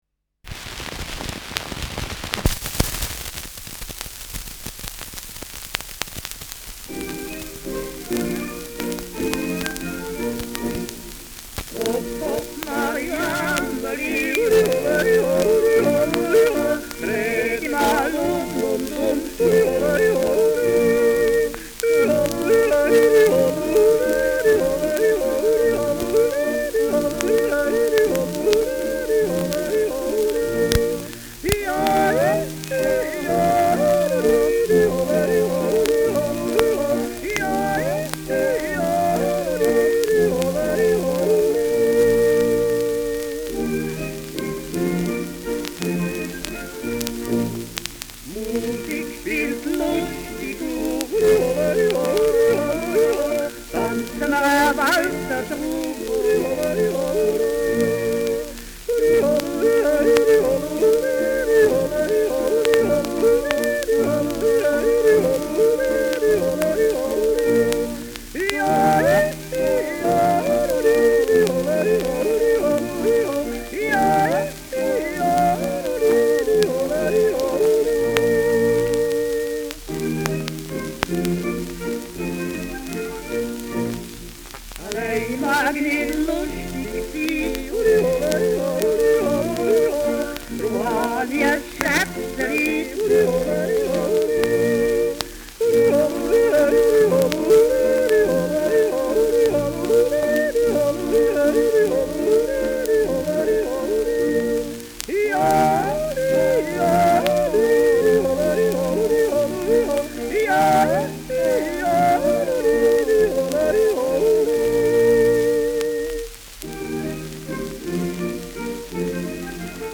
Schellackplatte
Stark abgespielt : Erhöhtes Grundrauschen : Teils verzerrt : Durchgehend leichtes bis stärkeres Knacken
Schweizer Jodel-Duett (Interpretation)
Jodlergruppe* FVS-00014